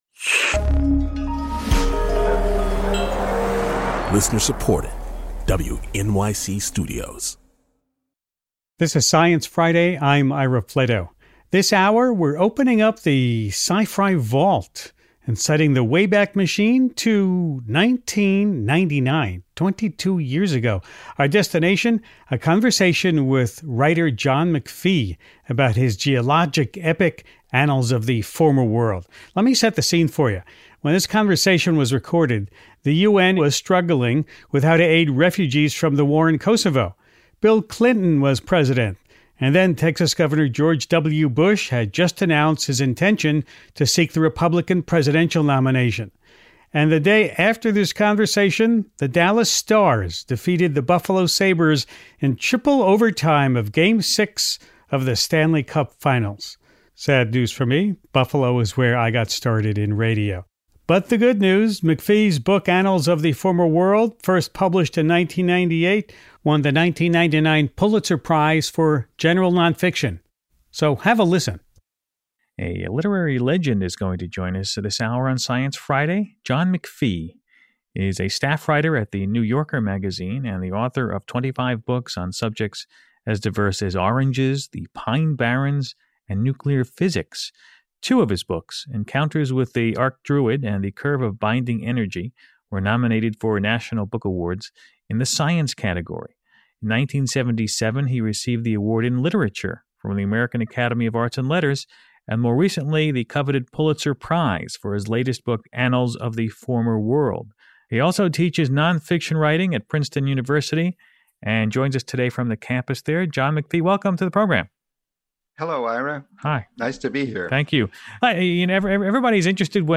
In this archival interview, recorded in June 1999, McPhee talks with Ira Flatow about the process of reporting Annals of the Former World, which had just won the Pulitzer Prize for general nonfiction. They talk about rocks, maps, and geology, of course—but also about characters, nuclear physics, migrating fish, and the craft of writing.